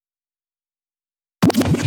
VTDS2 Song Kit 12 Rap 1 Out Of 2 FX Vinyl.wav